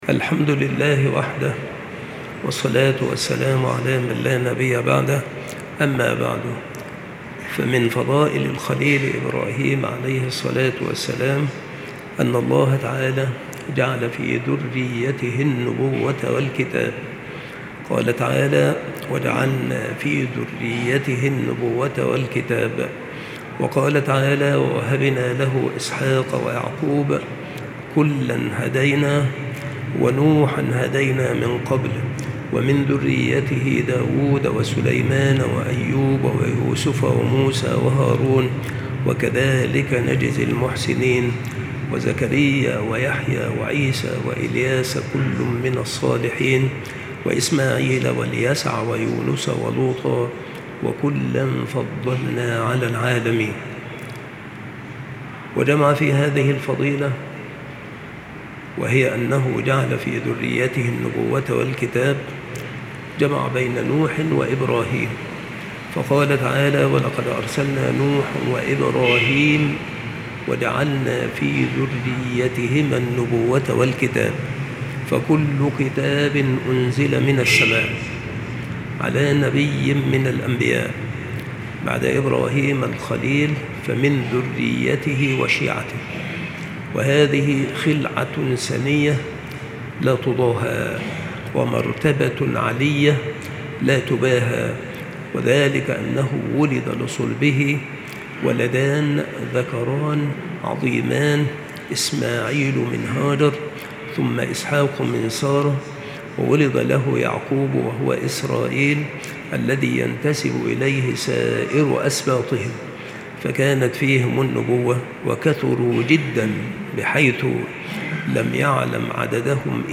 • مكان إلقاء هذه المحاضرة : بالمسجد الشرقي - سبك الأحد - أشمون - محافظة المنوفية - مصر